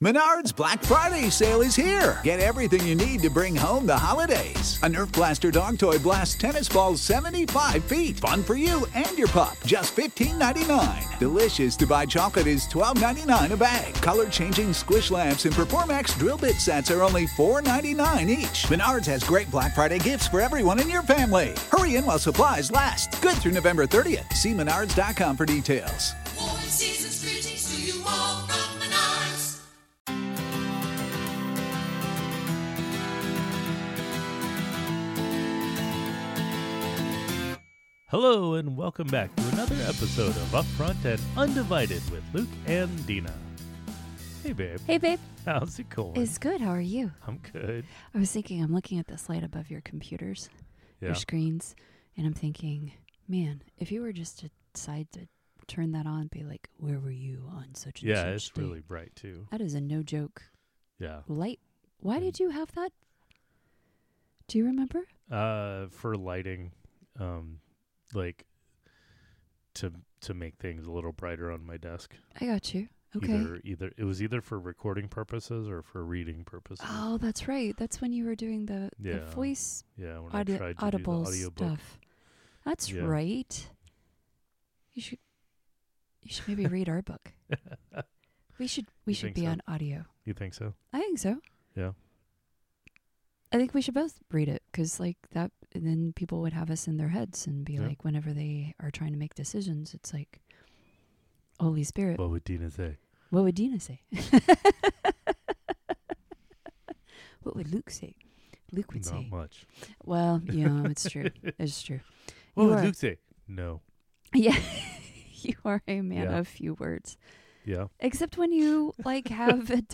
Real conversations with a real couple. In our podcast we will talk about and take on a variety of topics including: - Relationships/Marriage - Courting/Dating - Honor - Social Situations - Life - God and Christianity - Sex - Children - and any other random thing we might think of.